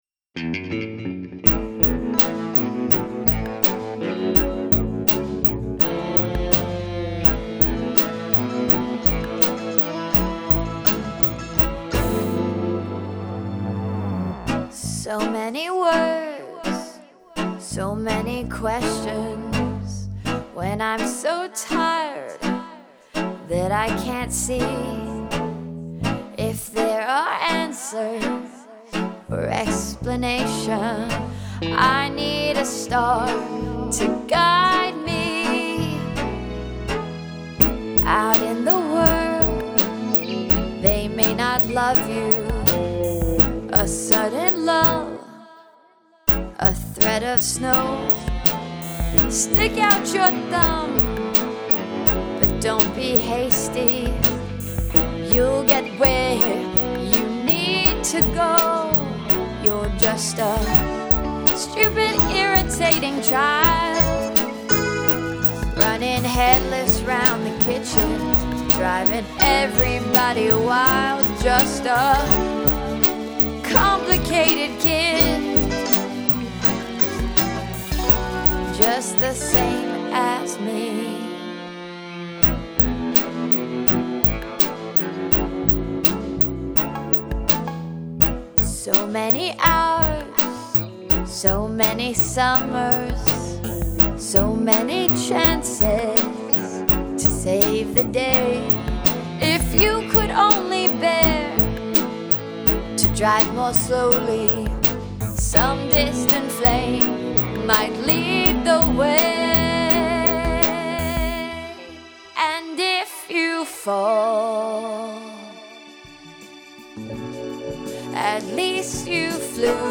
Guitars, BVs, keyboards etc: